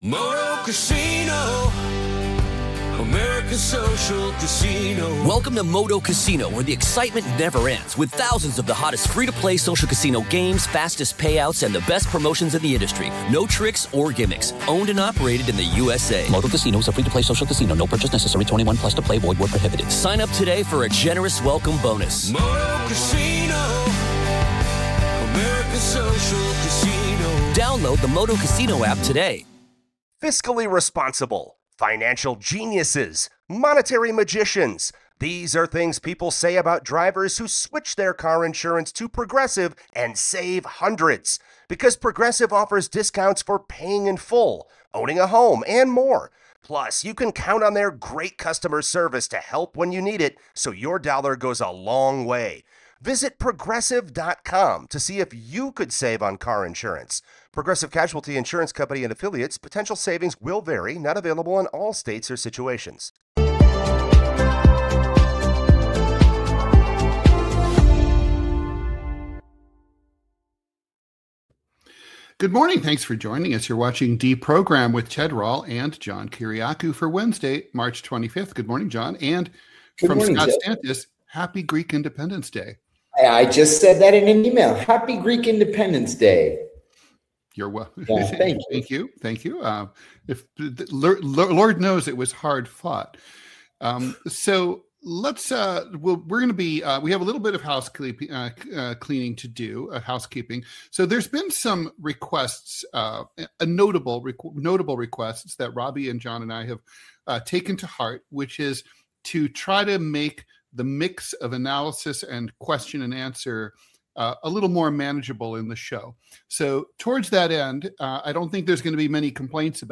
Political cartoonist Ted Rall and CIA whistleblower John Kiriakou deprogram you from mainstream media every weekday at 9 AM EST.